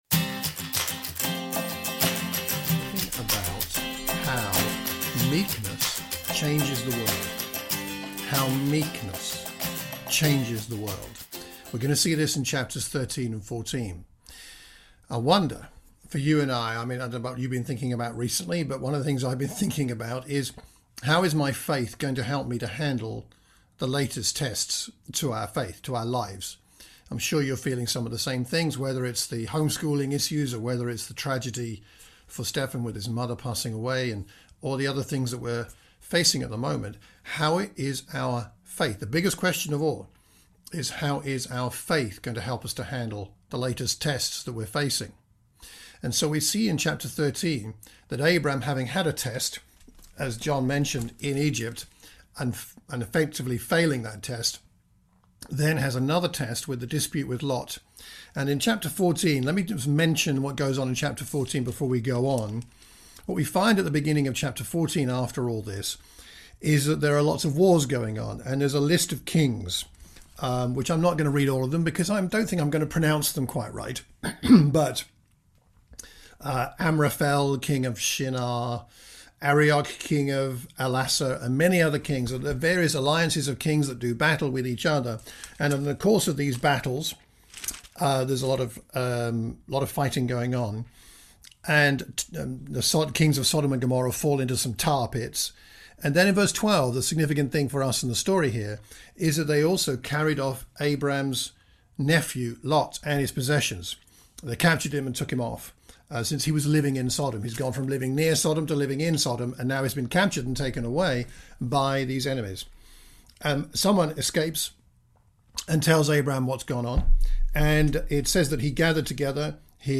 A sermon for the Watford church of Christ.